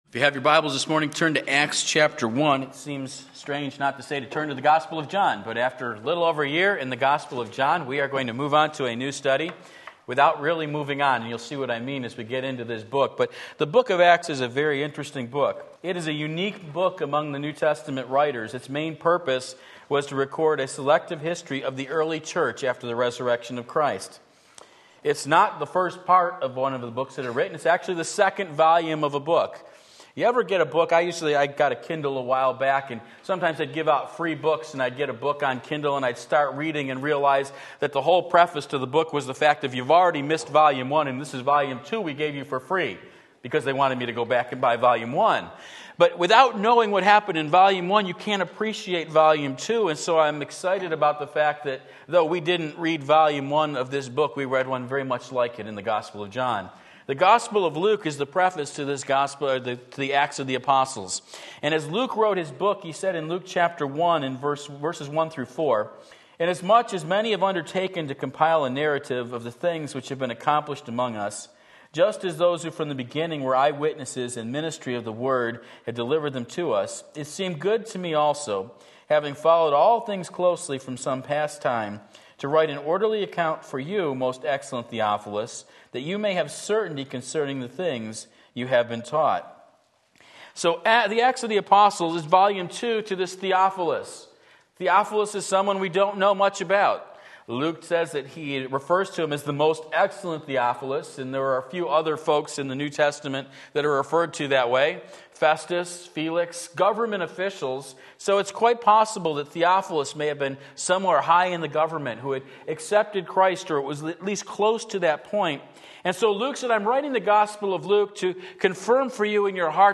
Sermon Link
The Journey Begins Acts 1:1-11 Sunday Morning Service